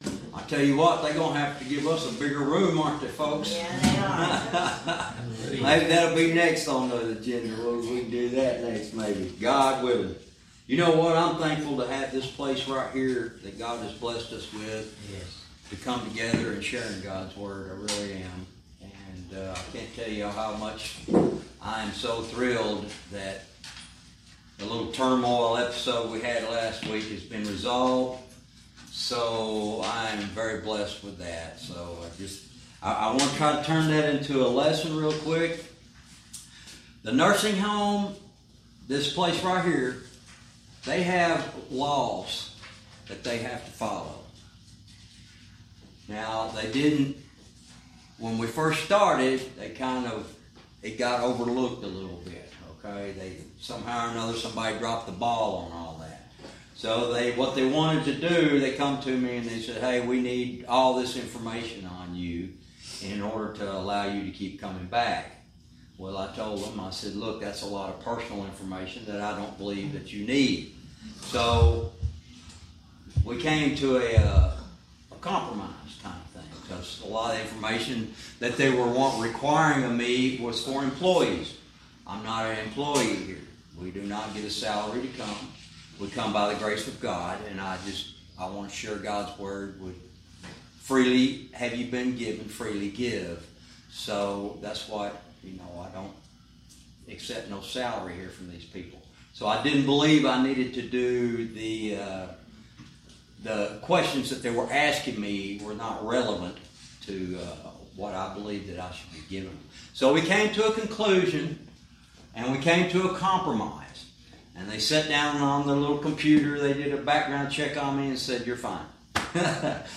Know Im Saved Bible Teaching